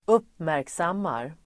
Uttal: [²'up:märksam:ar]